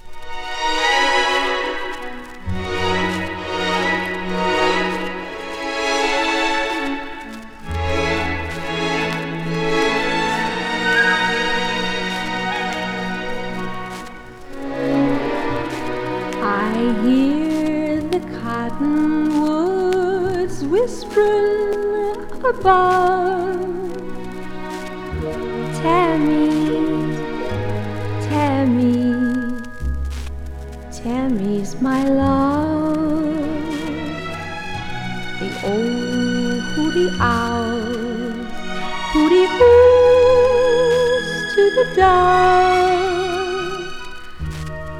Pop, Vocal, Stage & Screen　USA　12inchレコード　33rpm　Stereo